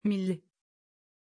Pronunciation of Millie
pronunciation-millie-tr.mp3